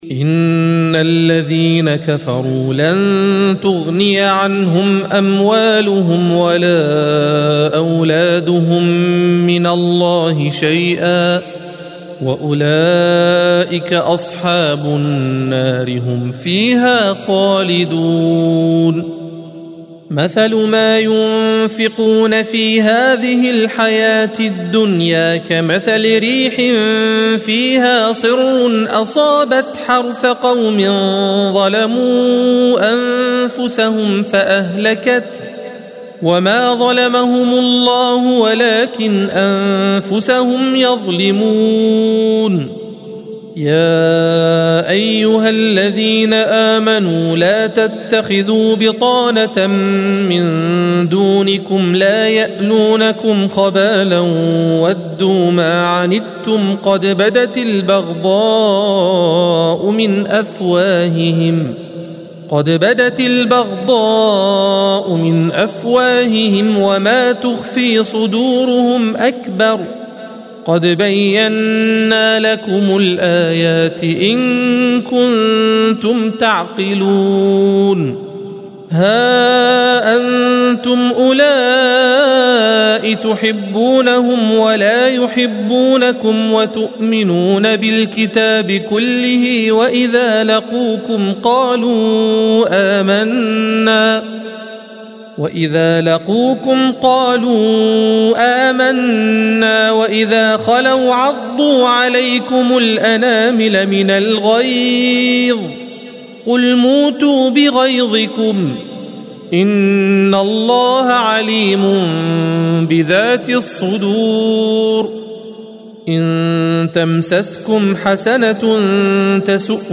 الصفحة 65 - القارئ